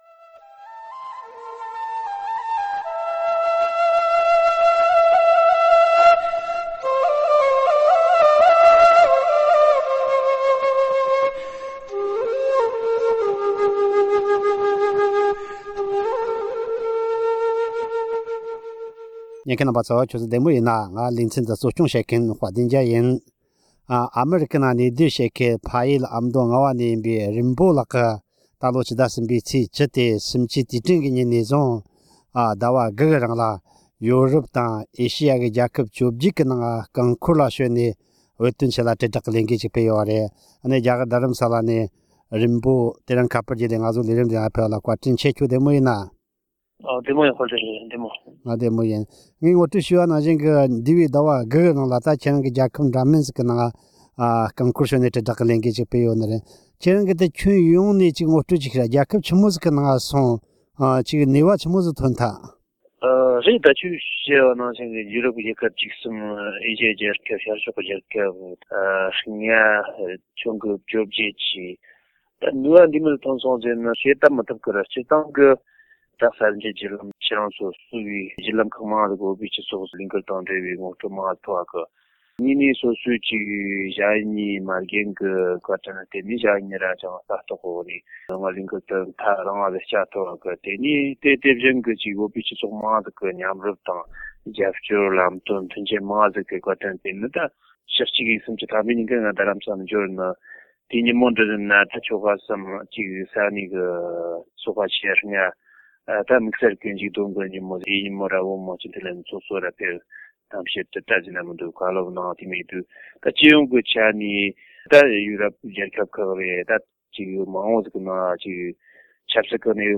གླེང་མོལ་ཞུས་པར་གསན་རོགས་གནང་།།